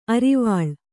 ♪ arivāḷ